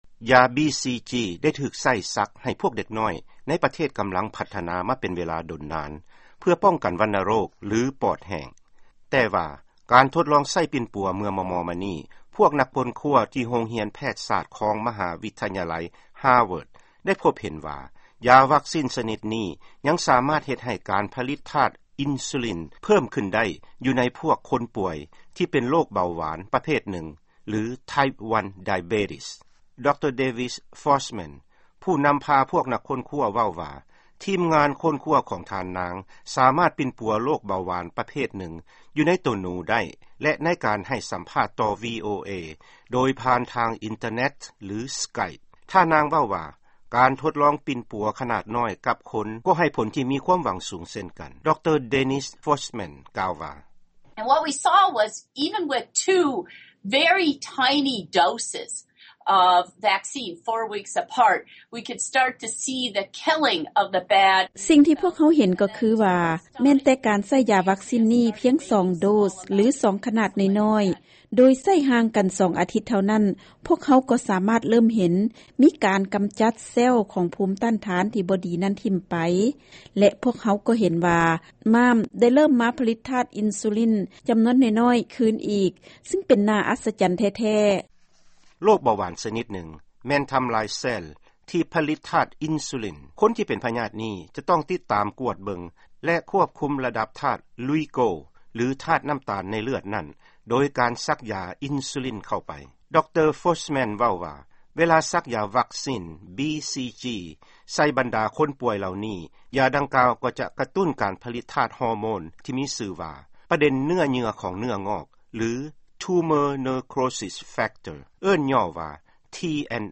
ຟັງຂ່າວກ່ຽວກັບຢາວັກຊິນ BCG